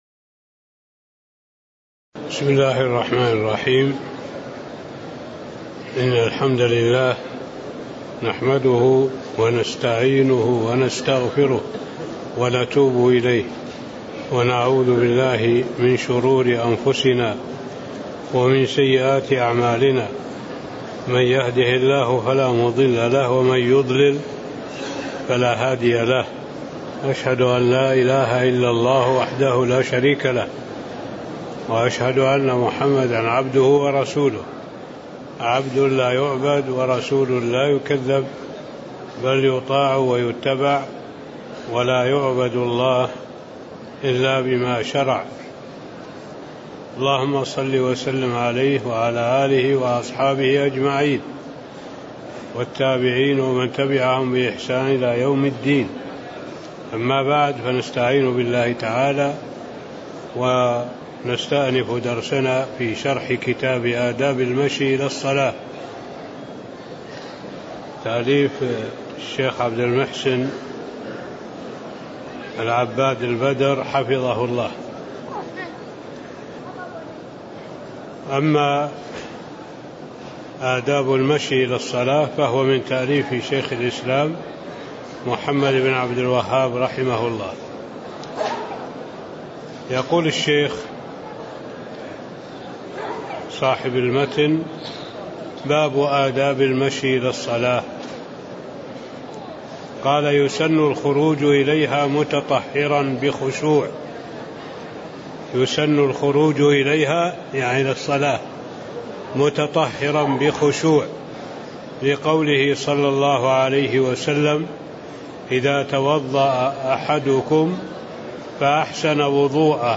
تاريخ النشر ٣ محرم ١٤٣٦ هـ المكان: المسجد النبوي الشيخ: معالي الشيخ الدكتور صالح بن عبد الله العبود معالي الشيخ الدكتور صالح بن عبد الله العبود باب أدآب المشي إلى الصلاة (06)